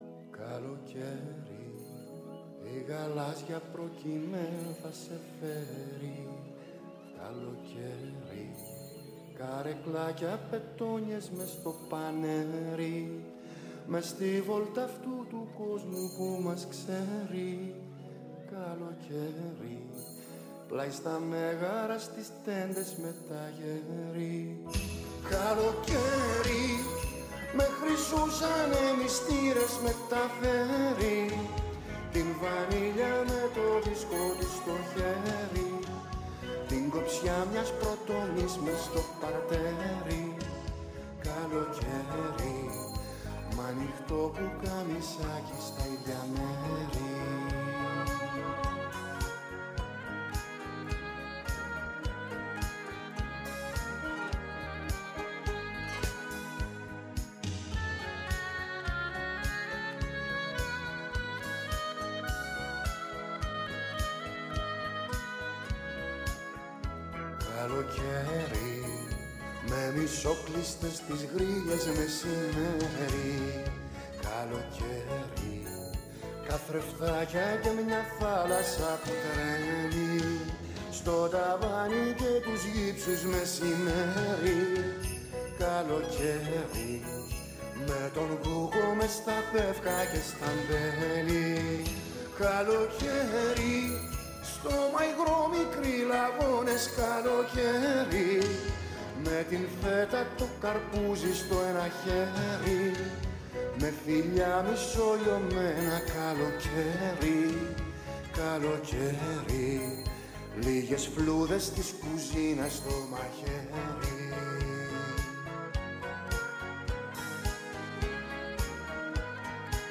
Στο στούντιο της ΦΩΝΗΣ ΤΗΣ ΕΛΛΑΔΑΣ